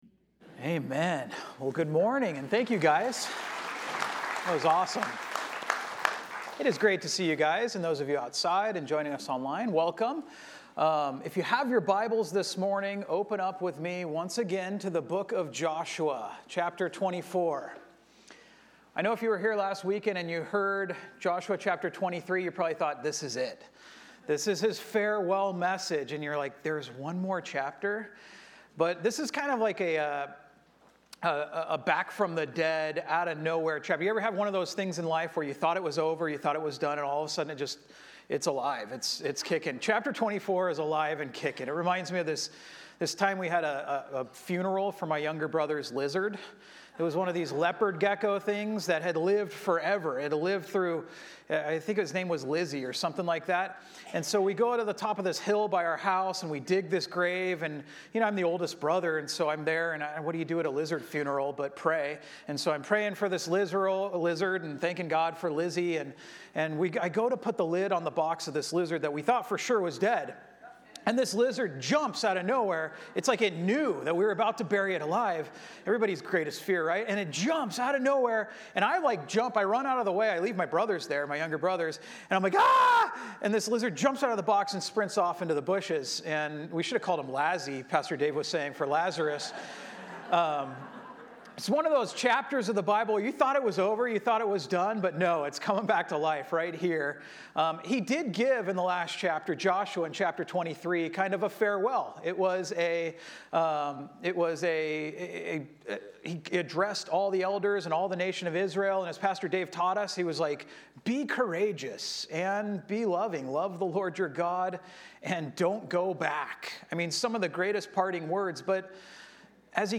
Messages | Pacific Hills Calvary Chapel | Orange County | Local Church